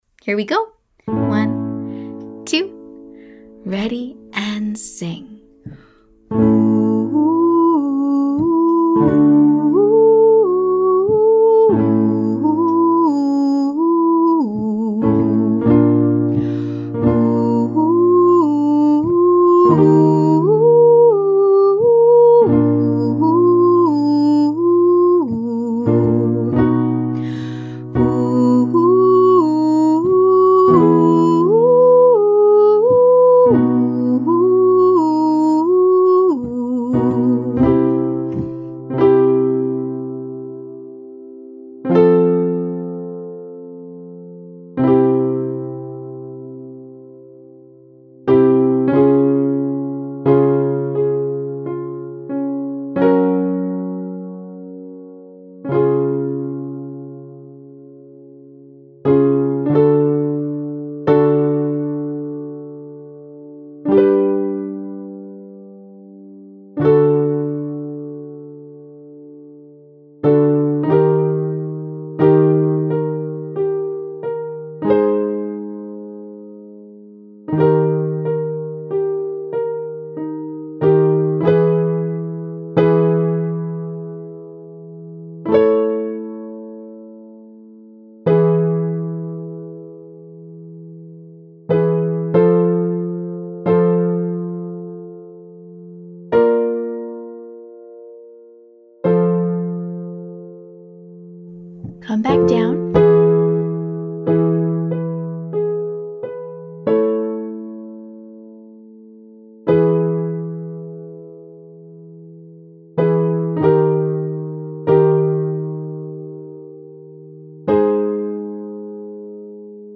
We basically have two patterns: 1, 2, 1 again, and we land on the pitch we started on.
Vocal Agility Lesson 5C